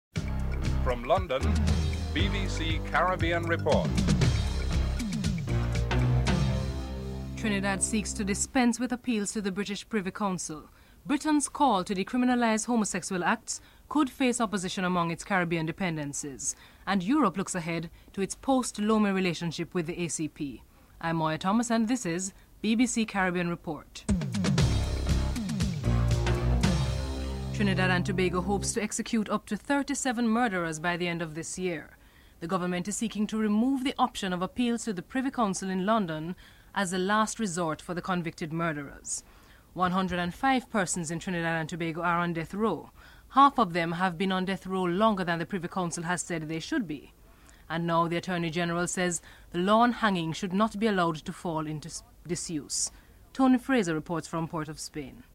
England's bowler Dean Headley talks about his West Indian roots and his commitment to England.
Headlines